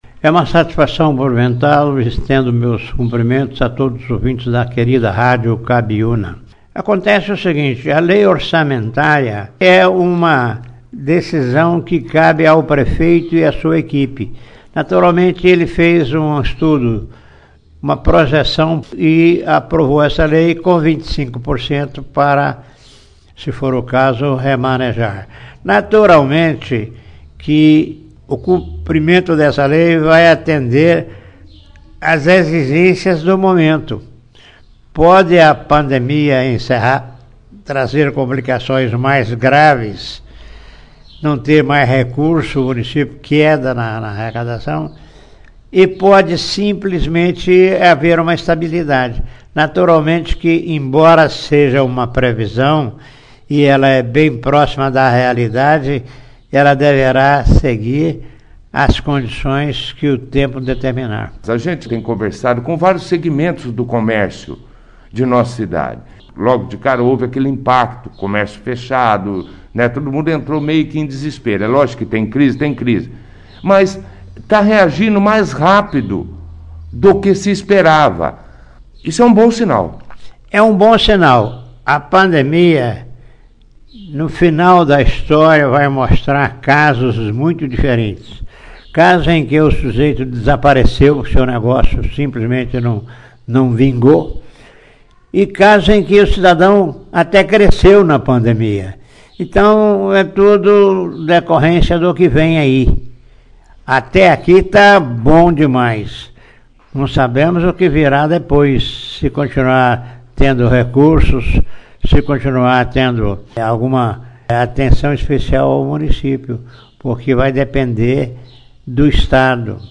O ex-prefeito hoje vereador José Fernandes da Silva (foto), participou da 1ª edição do jornal Operação Cidade desta quinta-feira,22/10, falando sobre o trabalho realizado no legislativo, referente a votação do orçamento para o ano de 2021 do município de Bandeirantes, e de não participar da disputa por um cargo nas eleições deste ano.